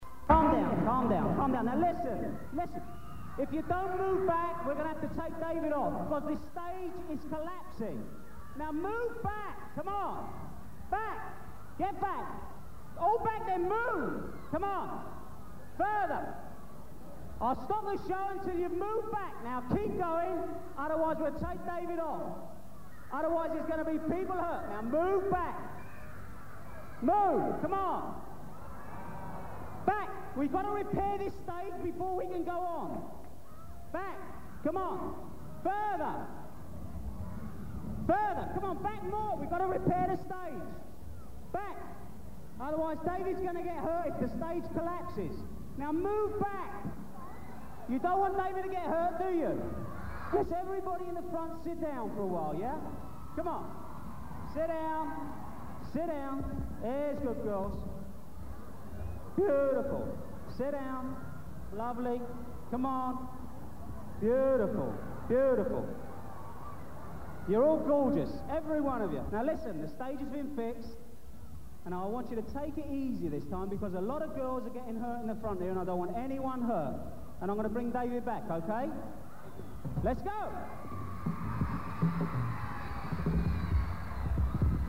stage collapsing.mp3